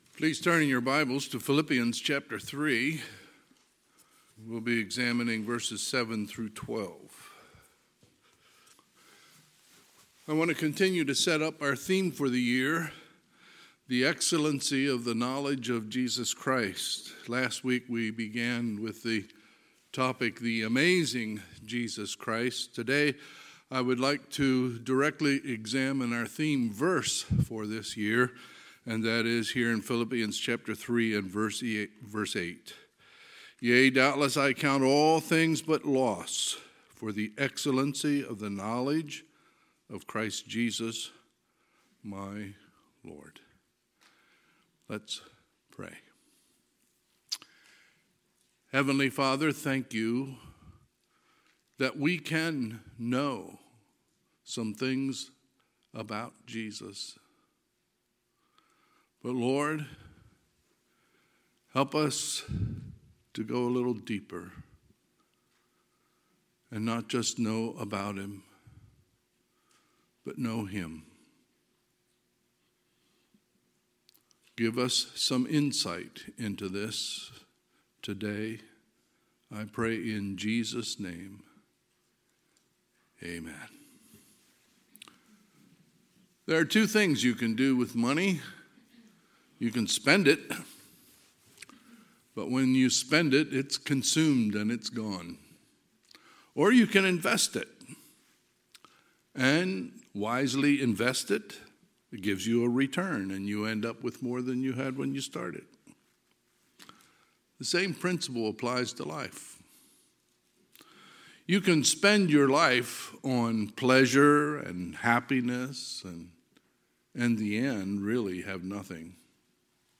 Sunday, January 8, 2023 – Sunday AM
Sermons